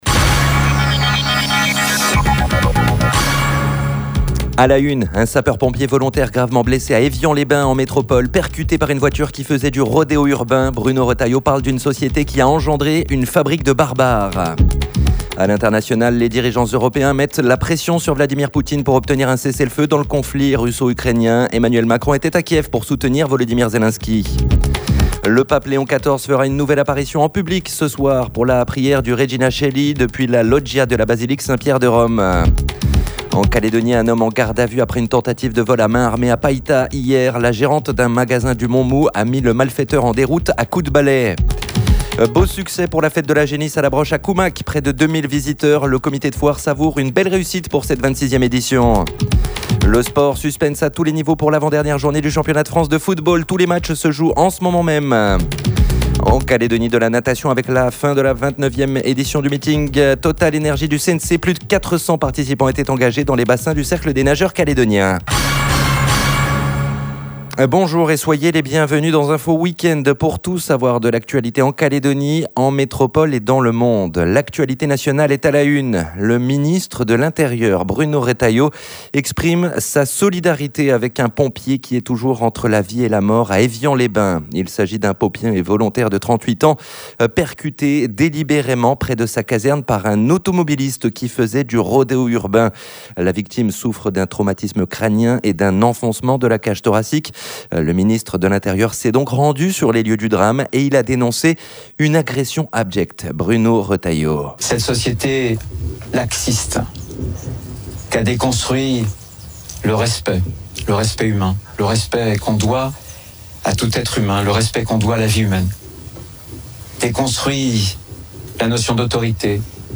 Info-weekend, votre grand journal du weekend, pour tout savoir de l'actualité en Calédonie, en métropole et dans le Monde.